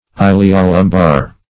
Search Result for " iliolumbar" : The Collaborative International Dictionary of English v.0.48: Iliolumbar \Il`i*o*lum"bar\, a. (Anat.) Pertaining to the iliac and lumbar regions; as, the iliolumbar artery.
iliolumbar.mp3